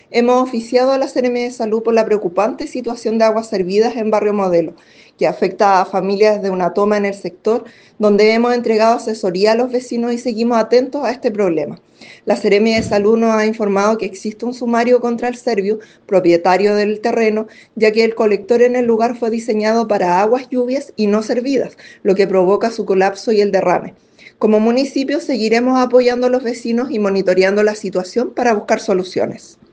Por parte de la Municipalidad de Concepción, la alcaldesa (s) Sintia Leyton explicó que desde la Dirección de Medioambiente se están tomando las medidas necesarias para resolver esta situación.